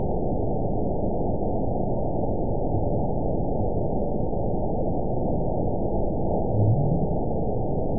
event 922726 date 03/22/25 time 22:10:44 GMT (8 months, 1 week ago) score 8.94 location TSS-AB02 detected by nrw target species NRW annotations +NRW Spectrogram: Frequency (kHz) vs. Time (s) audio not available .wav